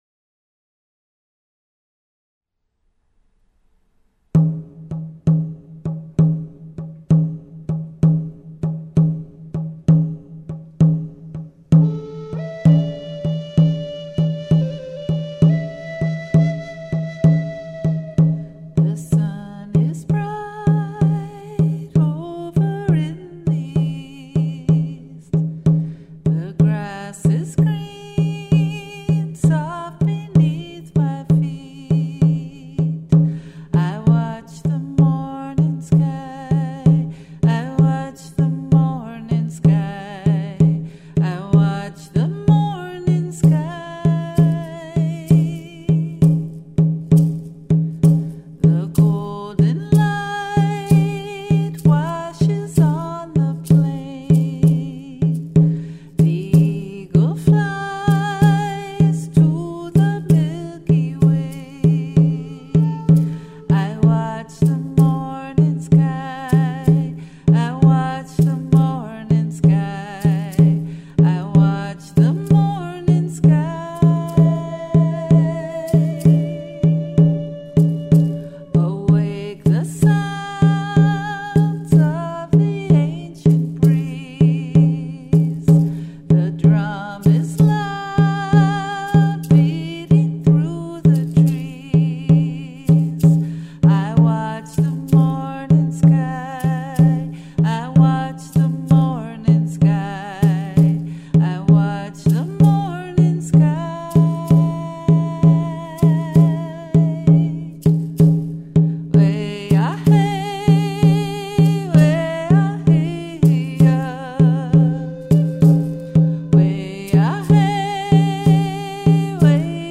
folk-rock